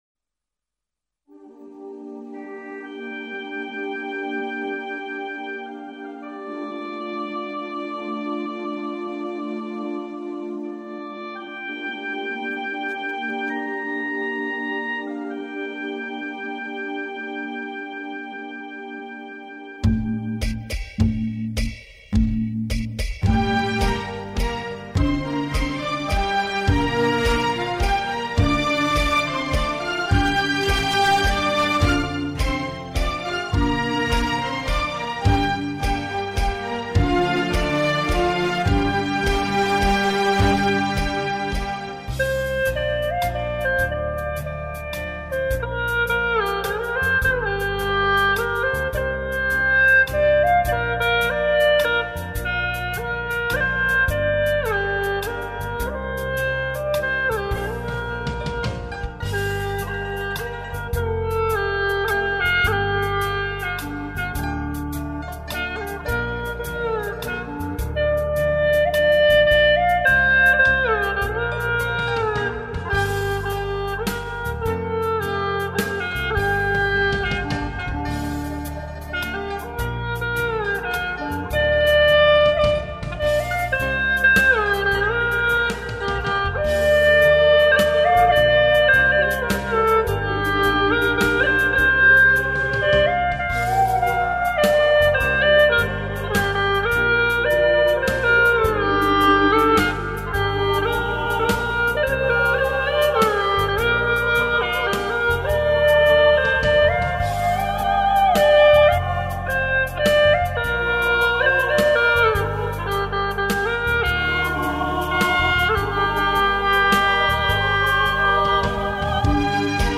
這個合奏集錦包括笛子與葫蘆絲、葫蘆絲二重奏、笛子與吉他的合奏。
葫蘆絲音色淳美，風味獨特，又形制簡樸易於上手，近年來頗火，受到文藝老中青少年們的寵愛。